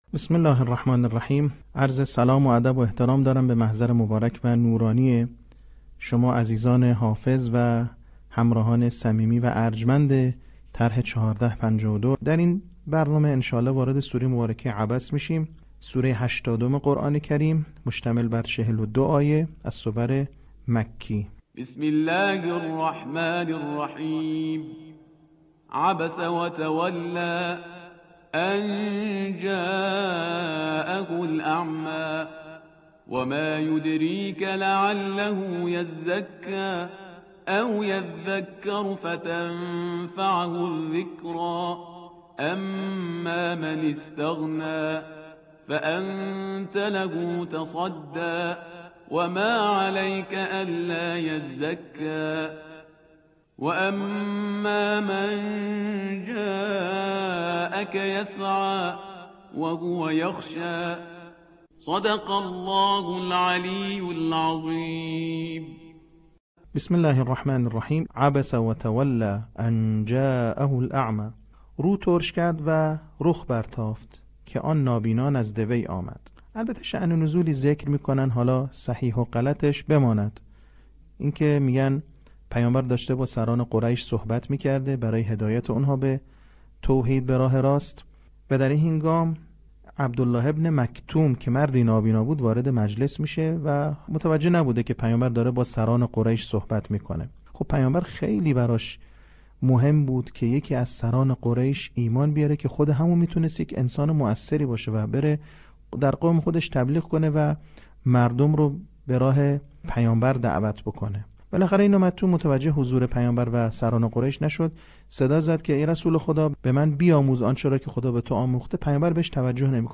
صوت | آموزش حفظ سوره عبس